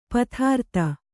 ♪ pathārta